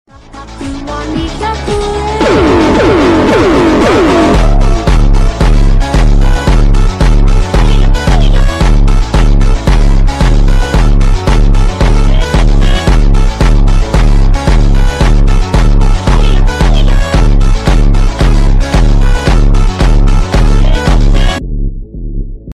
Поражение танка Leopard 2A6 ударом sound effects free download